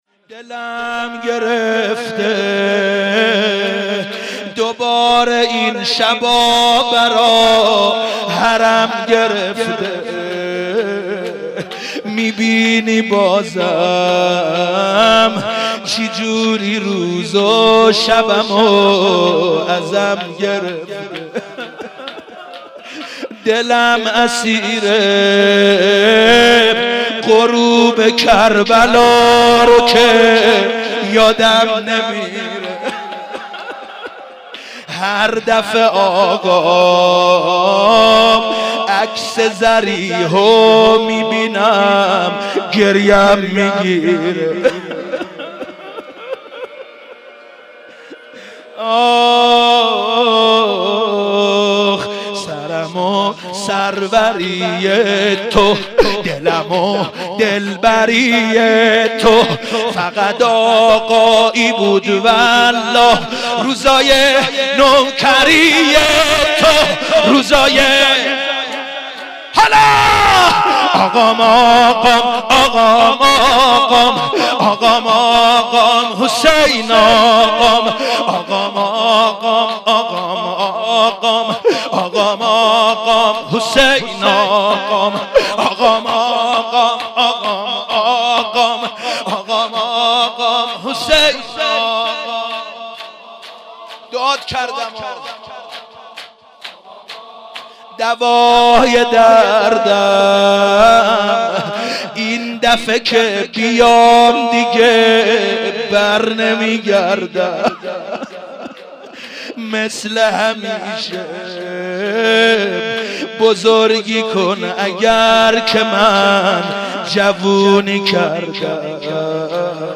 مناسبت : شهادت امام موسی‌کاظم علیه‌السلام
قالب : شور